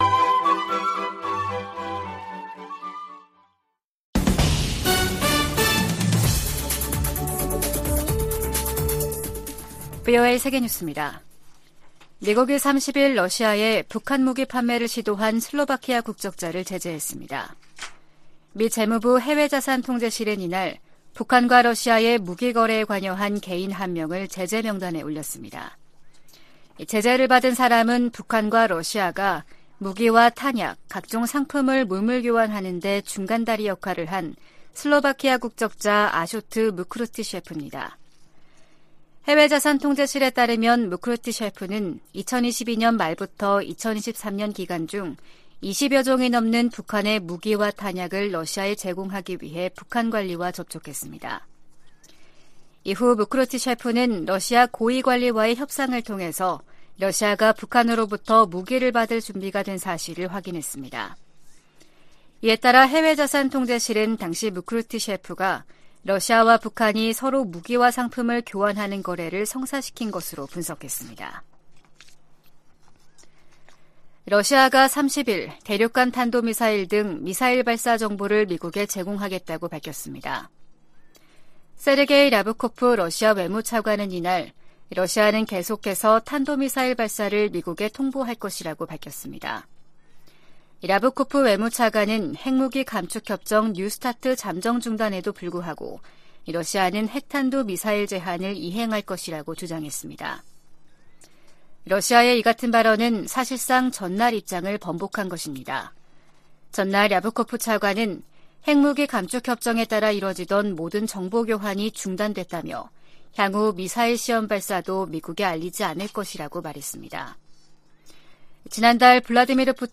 VOA 한국어 아침 뉴스 프로그램 '워싱턴 뉴스 광장' 2023년 3월 31일 방송입니다. 조 바이든 미국 대통령과 윤석열 한국 대통령은 제2차 민주주의 정상회의에서 공동성명을 내고 양국은 공동의 민주적 가치와 인권 존중을 기반으로 깊은 유대를 공유하고 있다고 밝혔습니다. 미 국무부는 한반도 비핵화 의지를 거듭 확인했습니다. 미국 공화당 중진 상원의원이 한국과의 핵 연습을 확대해야 한다고 주장했습니다.